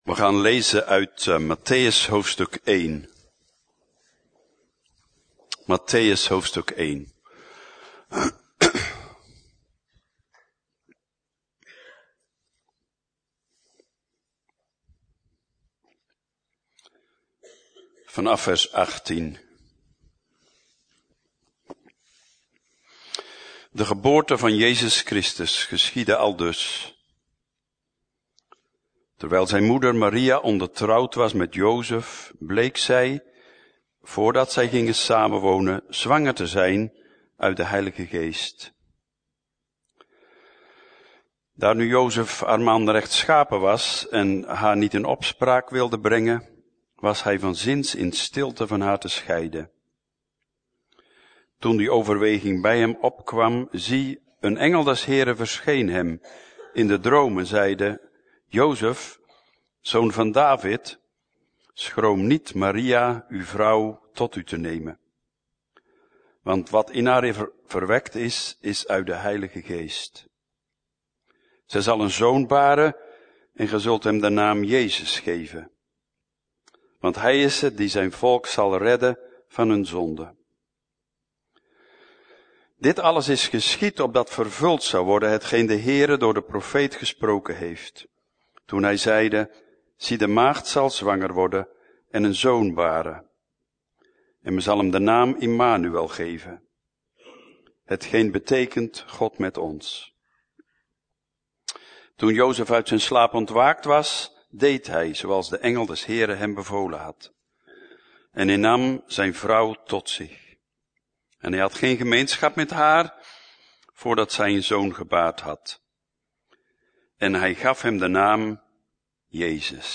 Preek: Jozef en Maria - Levende Hoop